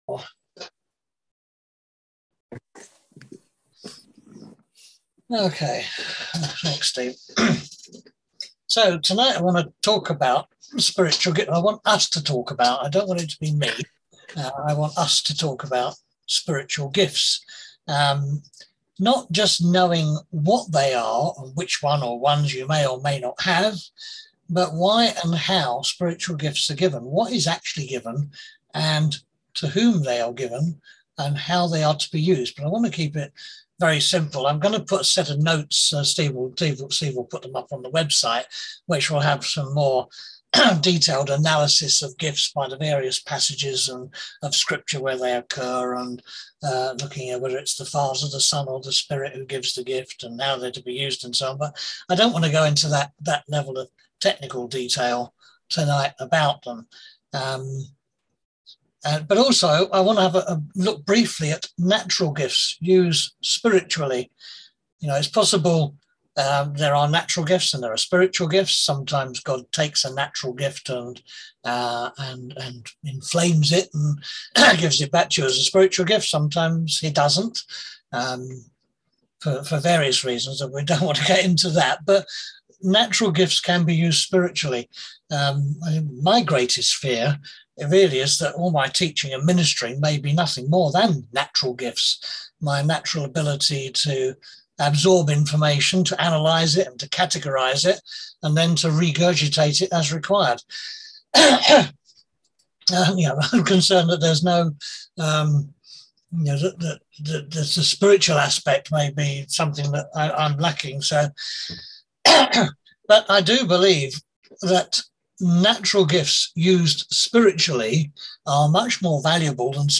On August 18th at 7pm – 8:30pm on ZOOM